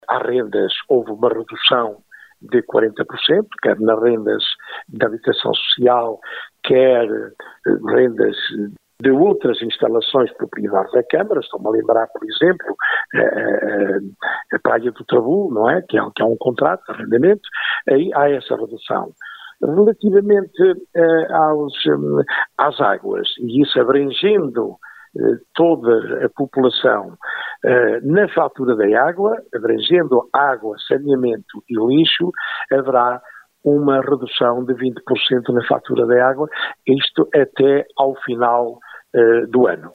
Paulo Santos, presidente da Câmara do Sátão, diz que a verba vai ser atribuída ao comércio que, durante o Estado de Emergência têm a porta fechada ou que estão a funcionar parcialmente.
As taxas de utilização de esplanadas e do terrado na feira semanal também sofrem uma redução que se prolonga até ao final do ano refere o autarca Paulo Santos.